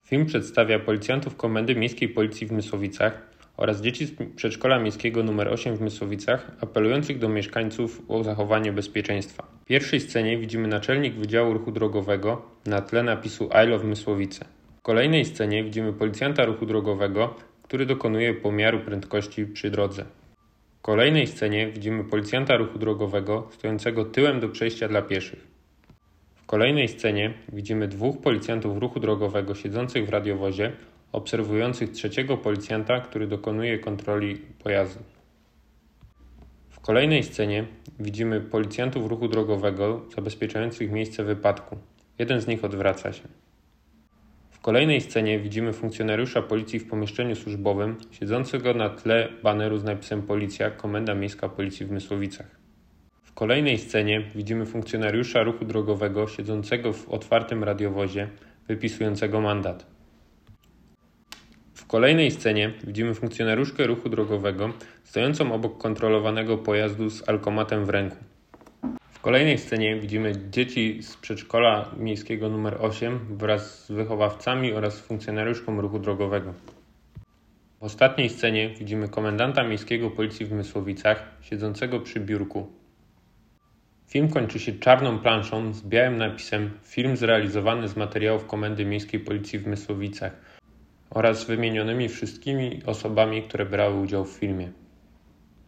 Mysłowiccy mundurowi zrealizowali spot, w którym osobiście apelują do mieszkańców o to, by przestrzegali zasad bezpieczeństwa w ruchu drogowym.